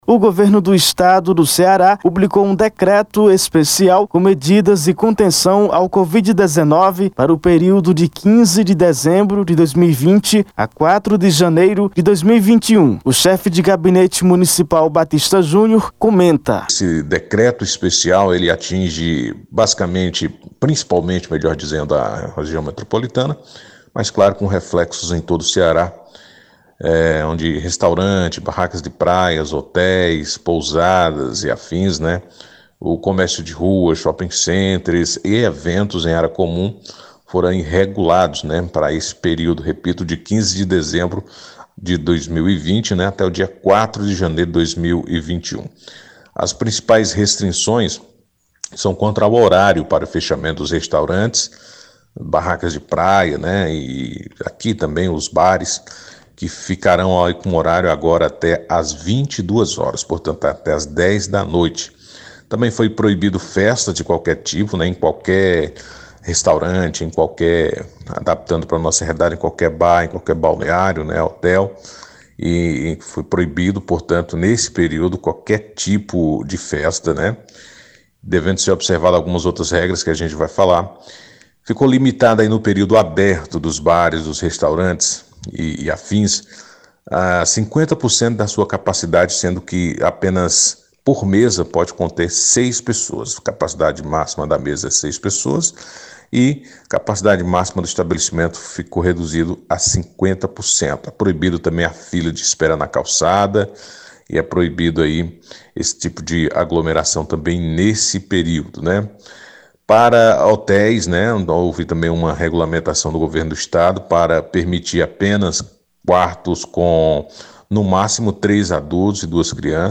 Confira a reportagem de áudio: Foto aérea de Várzea Alegre | Drone...